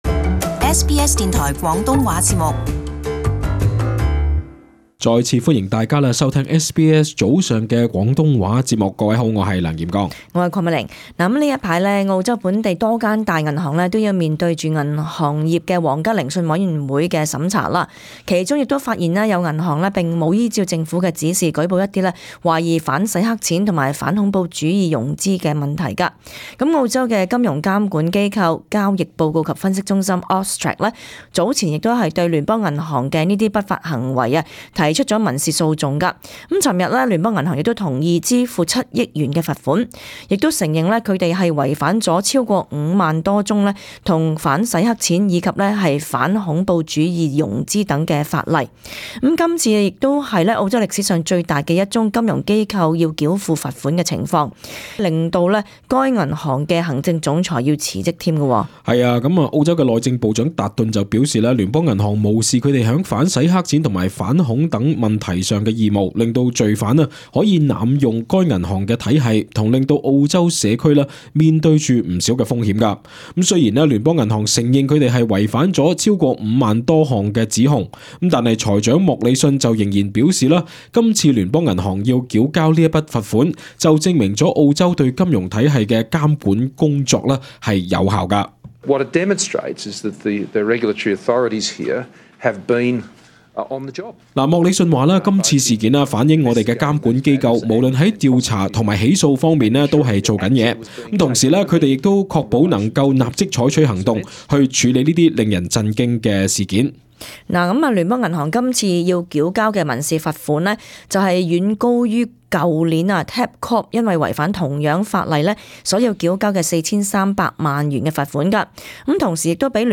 【時事報導】聯邦銀行違例被罰破紀錄7億